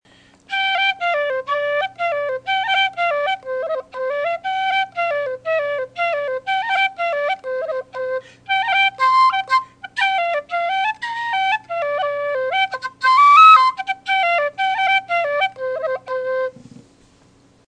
The D/C set I have has a husky tone. It’s rich and complex.
Fraher’s Jig on the C whistle